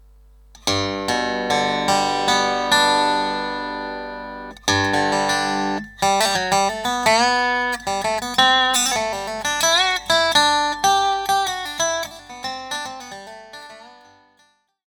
Zvuky snímačů – Custom Resophonic Guitars
(Zvuky jsou bez přidaných efektů a předzesilovačů jako např. Fishman AURA atd…)
Snímač McIntyre Acoustic Feather (piezo-elektrický instalovaný na rezonátoru):